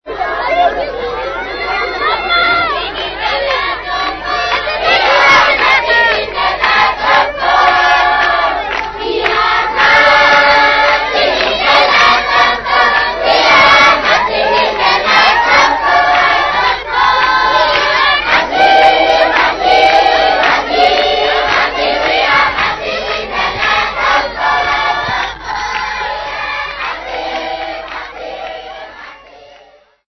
Doringveld Congregation
Folk music
Field recordings
sound recording-musical
After Mass - Children singing religious song.